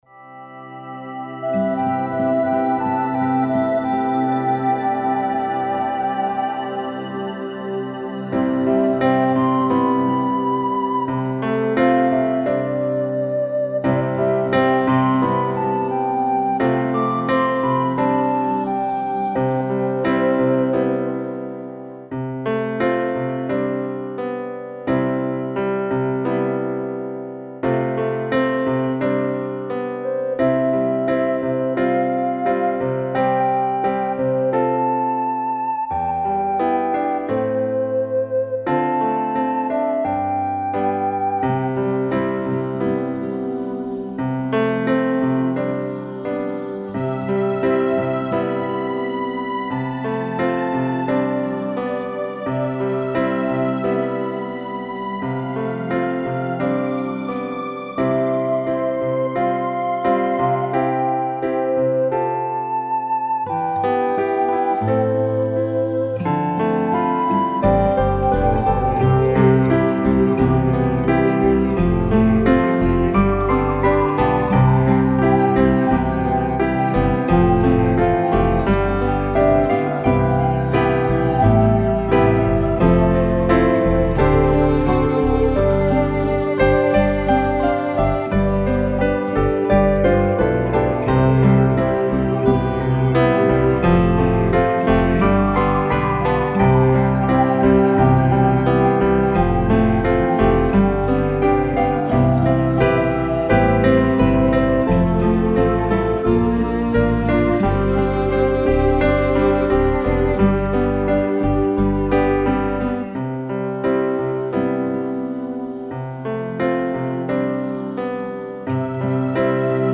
Then I recorded my guitar into those gorgeous arrangements in a new way.
I share below some recent examples of my guitar playing added into these revised arrangements: